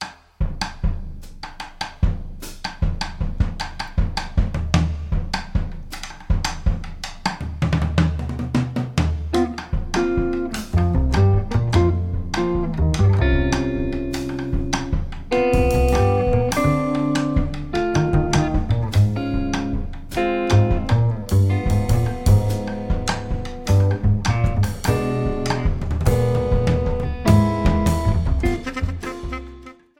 clarinet, bass clarinet, tenor saxophone
electric guitar
double bass
drums
Recorded on April 15, 2018, at Tracking Room, Amsterdam.